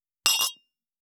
267,ショットグラス乾杯,乾杯,アルコール,バー,お洒落,モダン,カクテルグラス,ショットグラス,おちょこ,テキーラ,シャンパングラス,カチン,チン,カン,ゴクゴク,プハー,シュワシュワ,
コップ